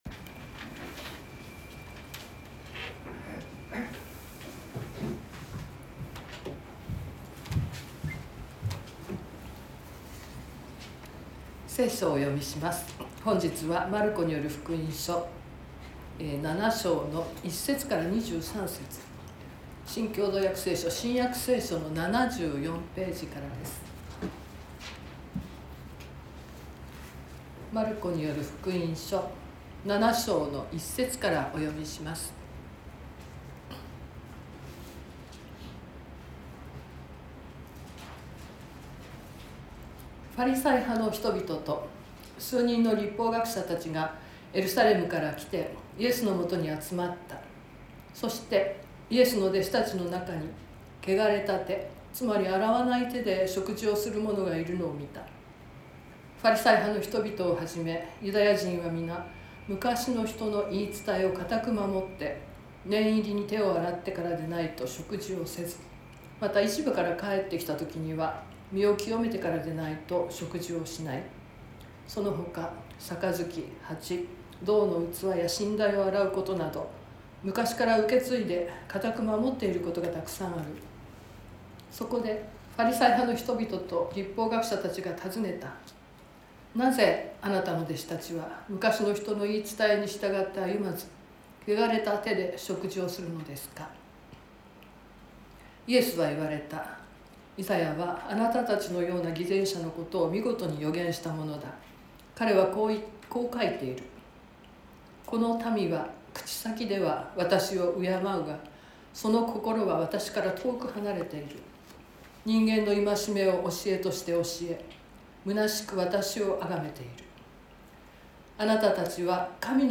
川越教会。説教アーカイブ。
Youtubeで直接視聴する 音声ファイル 礼拝説教を録音した音声ファイルを公開しています。